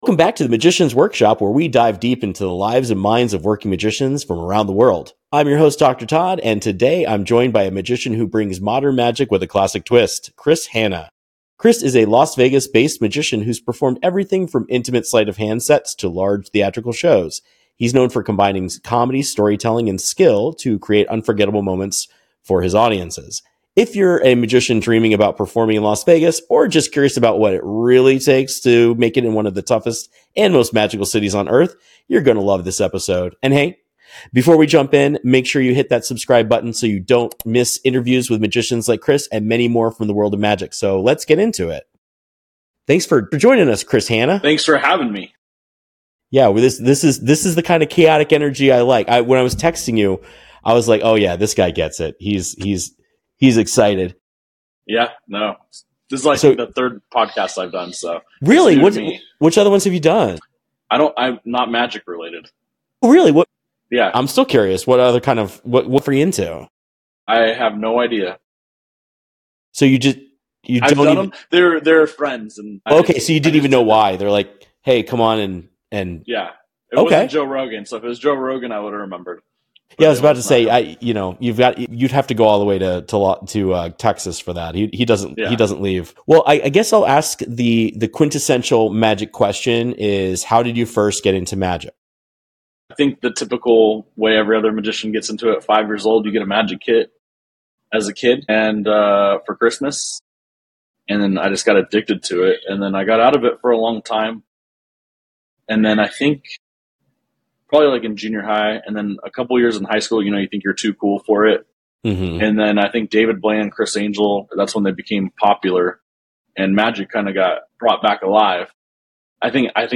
This conversation is packed with personal stories, career advice, and real talk every aspiring magician needs to hear.